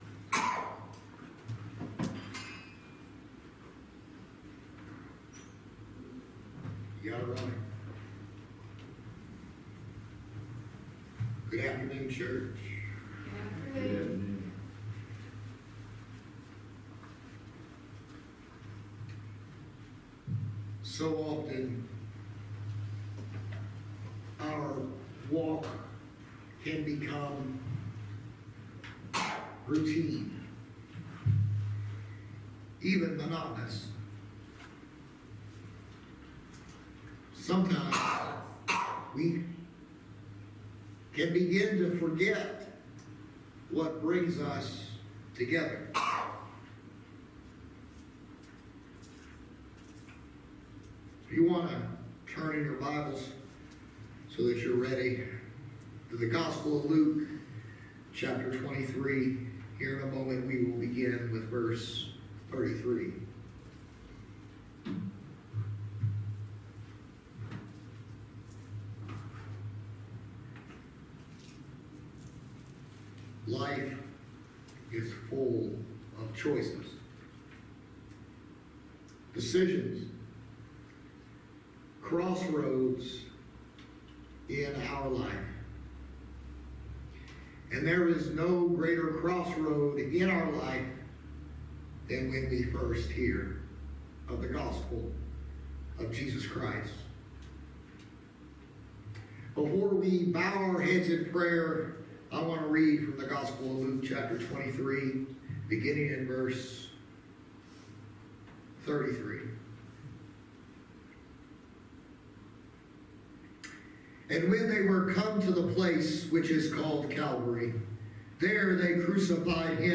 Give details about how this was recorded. Passage: Luke 23:33-38, Acts 7:59-60, Luke 23:39-43, Rom 10:9-10 Service Type: Sunday Service